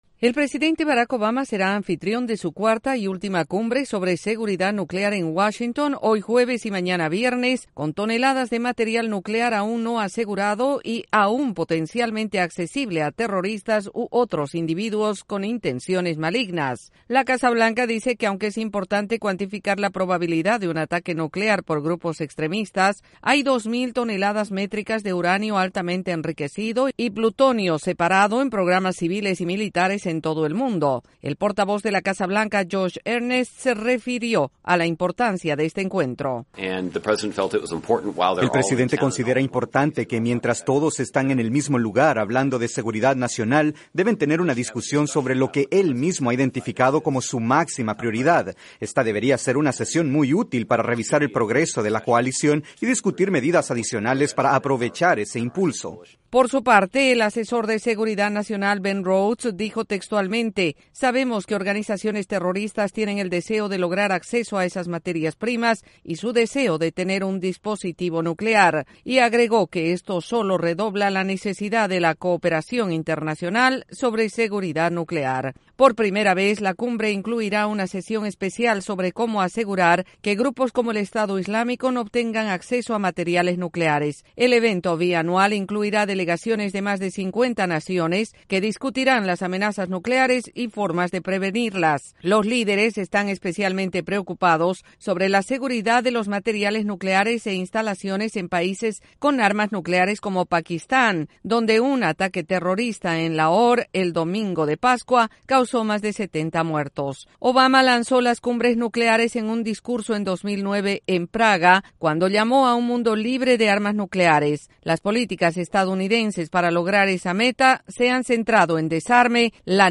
La Cumbre de Seguridad Nuclear se inicia con la participación de importantes líderes mundiales y será la última de la presidencia de Barack Obama . Desde la Voz de América en Washington DC informa